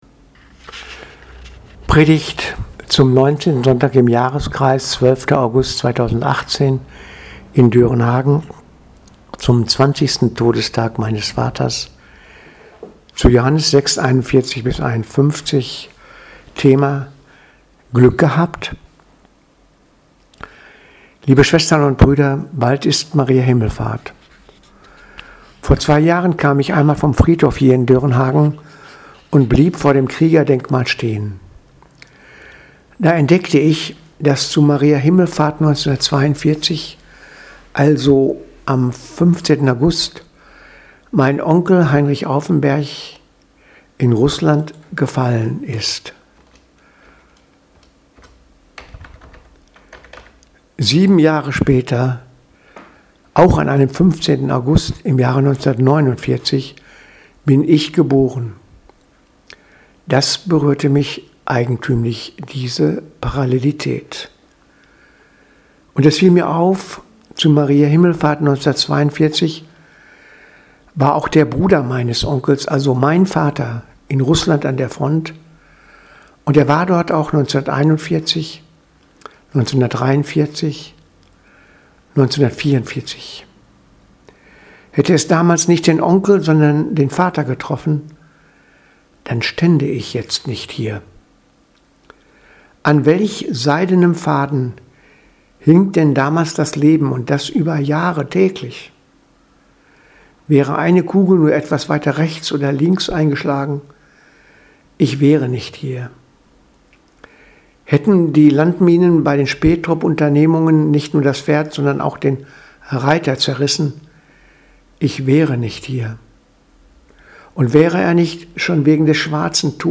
Predigt vom 12.08.2018 – Glück gehabt